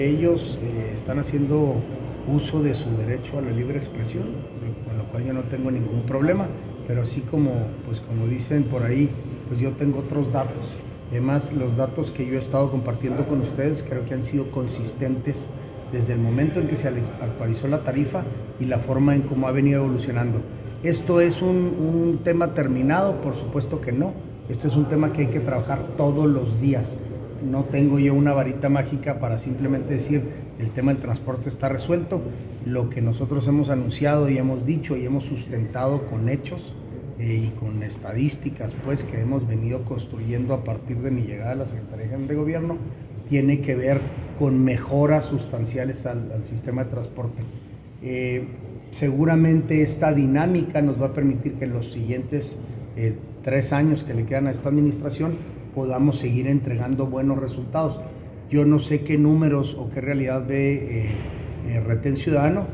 En respuesta, el secretario general de Gobierno, Santiago De La Peña, mencionó que, por el contrario, la dependencia a su cargo ha logrado «mejoras sustanciales» en el servicio, con énfasis a partir del 5 de abril de 2023, cuando, al acordar con los concesionarios la modernización de las unidades, el Consejo Consultivo de Transporte autorizó un aumento en la tarifa a $12 pesos en las rutas alimentadoras y $10 en las troncales.